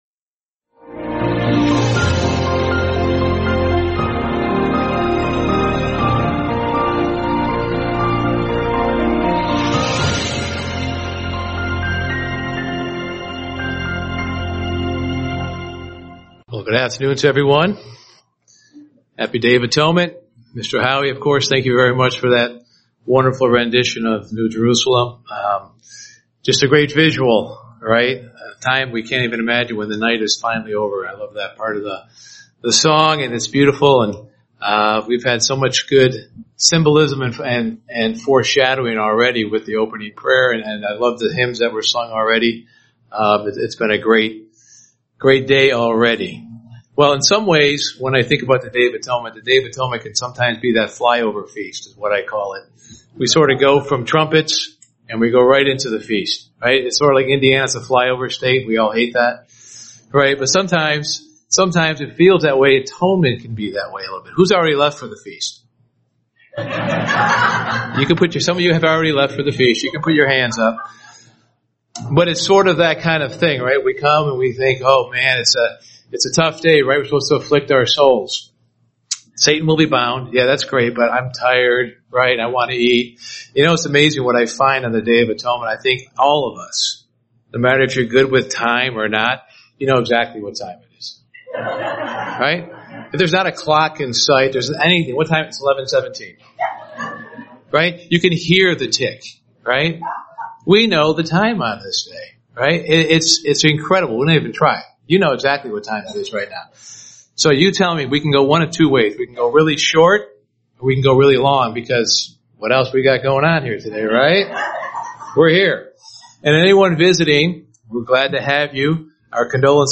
Given in Indianapolis, IN